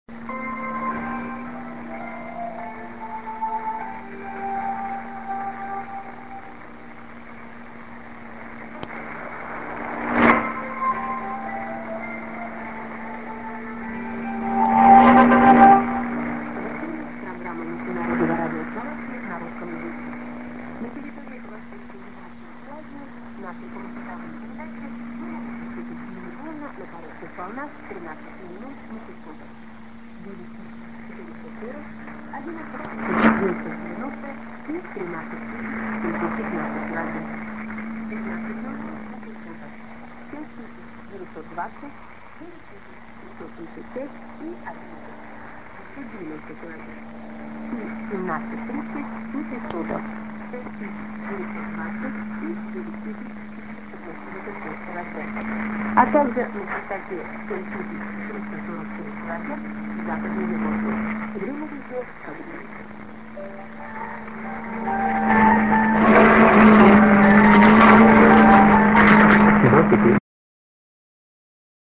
St. IS->ID:"this is ･…Radio Slovakia･…"(women)